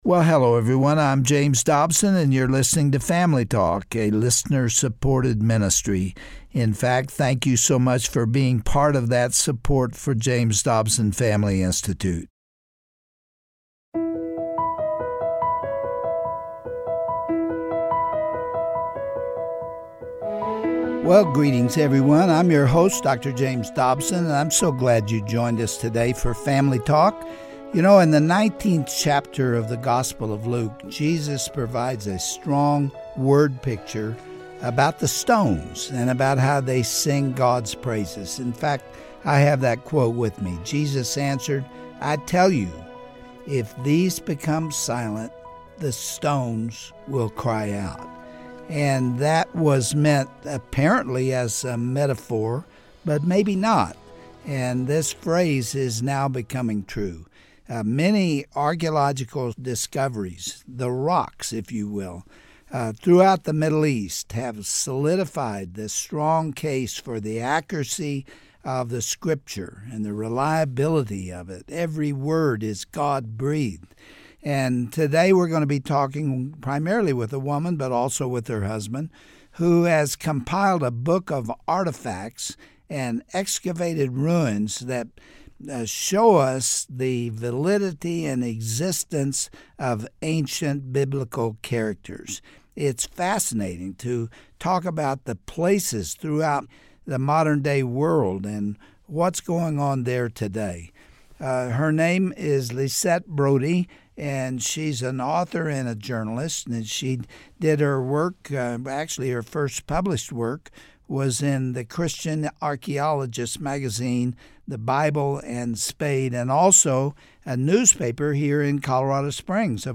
For many years, archeological findings have made the validity and accuracy of the Bible undeniable. On today’s edition of Family Talk, Dr. James Dobson interviews